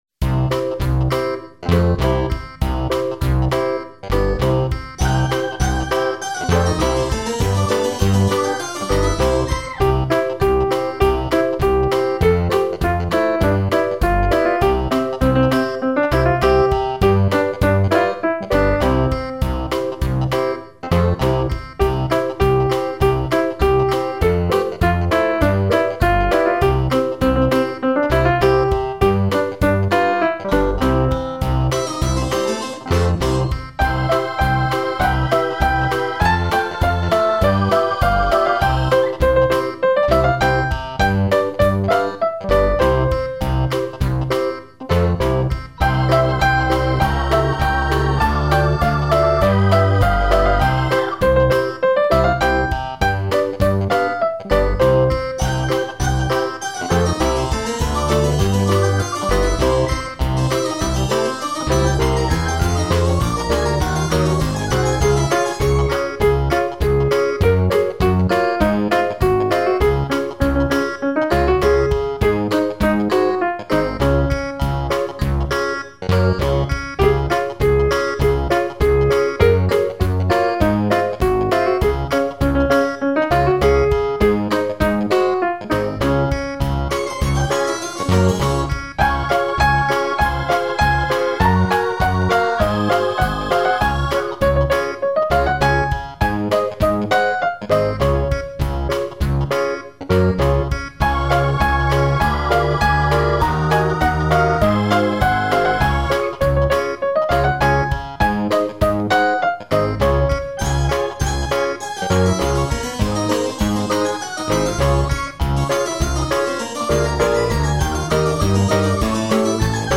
描述：儿童音乐|欢快
Tag: 合成器 风琴